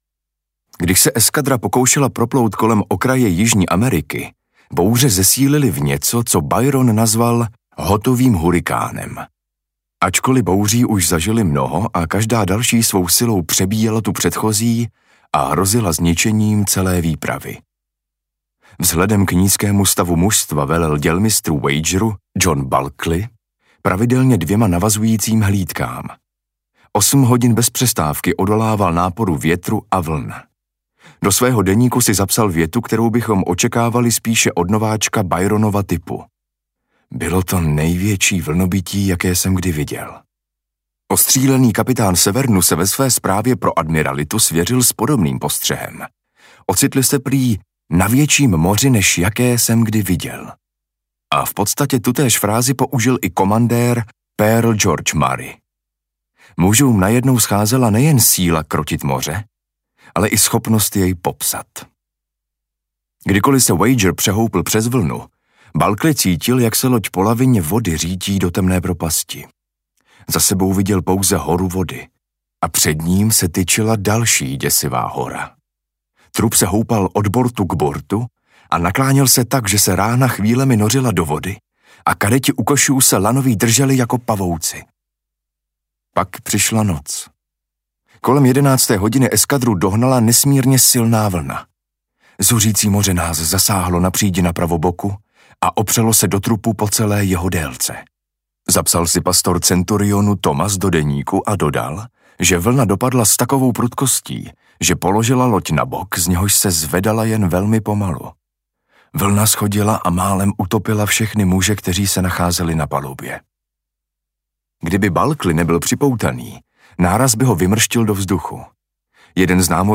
Audiokniha Wager - David Grann | ProgresGuru